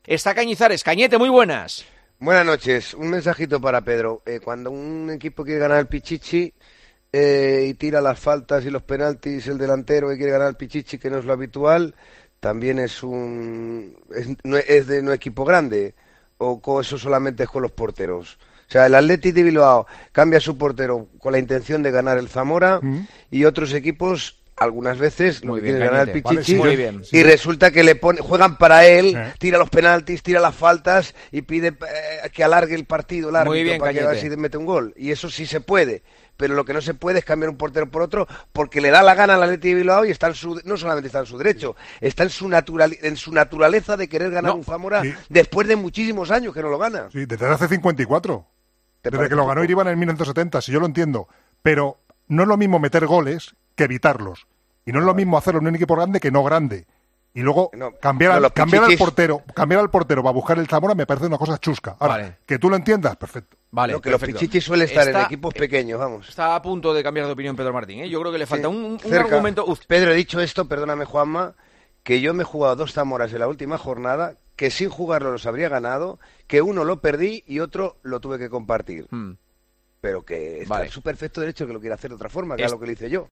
Durante el debate sobre esta decisión de Ernesto Valverde de cambiar a sus porteros, Cañizares defendió con vehemencia esta situación: "Cuando un equipo quiere ganar el Pichichi, el delantero quiere tirarlo todo ¿también no es de equipo grande o solo es por los porteros?".